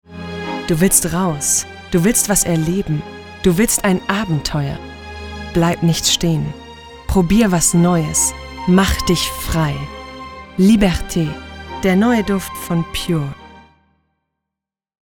Tief, freundlich, warm
Sprechprobe: Werbung (Muttersprache):
Werbung_0.mp3